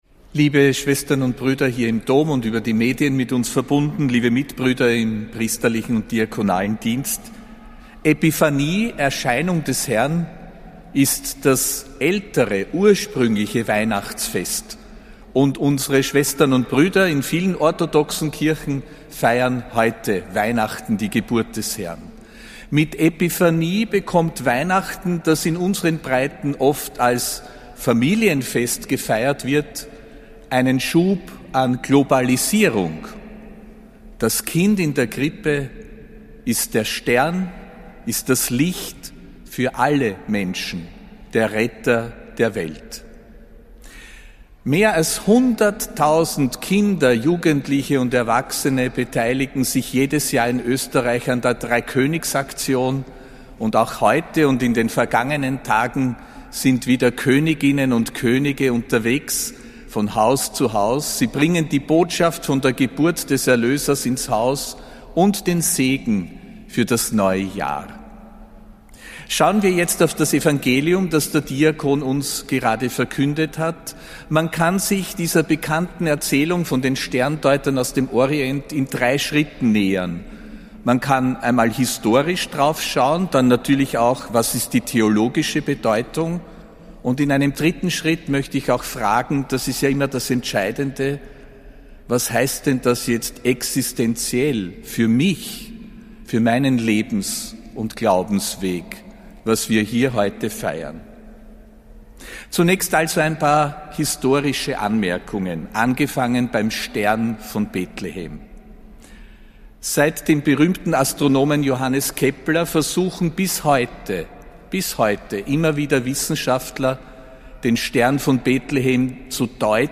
Predigt des Ernannten Erzbischofs Josef Grünwidl zu Dreikönig, am 6. Jänner 2026.